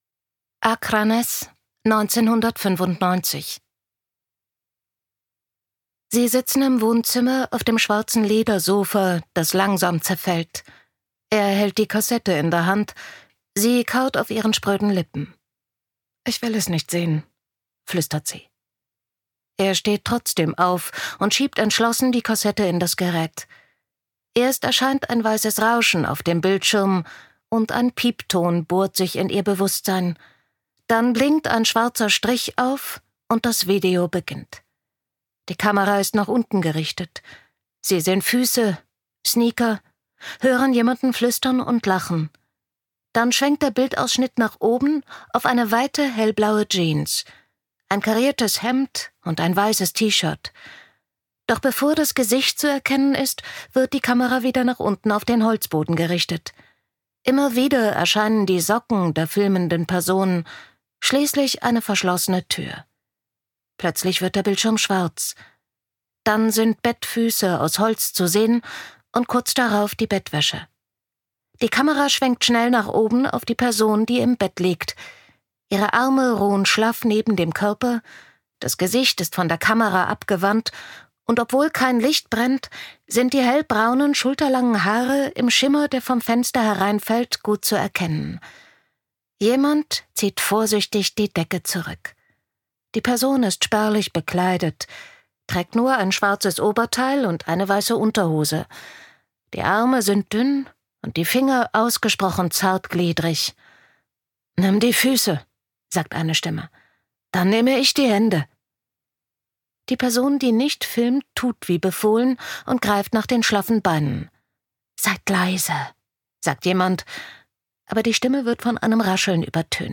Ein spannendes Hörbuch, das zeigt, dass die schlimmsten Verbrechen oft aus den tiefsten Wunden der Jugend erwachsen.
Gekürzt Autorisierte, d.h. von Autor:innen und / oder Verlagen freigegebene, bearbeitete Fassung.